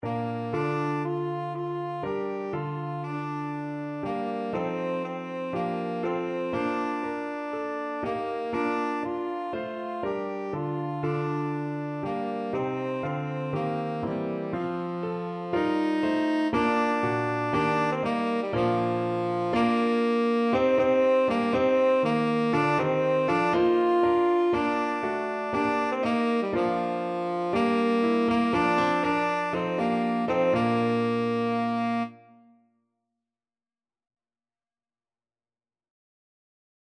Tenor Saxophone
Allegro = c. 120 (View more music marked Allegro)
Bb major (Sounding Pitch) C major (Tenor Saxophone in Bb) (View more Bb major Music for Tenor Saxophone )
4/4 (View more 4/4 Music)
Traditional (View more Traditional Tenor Saxophone Music)
go_tell_it_TSAX.mp3